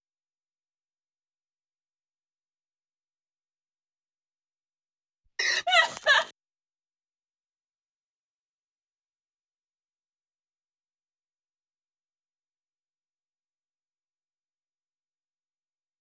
FA Marathon_Starting Next Monday15_SOT.wav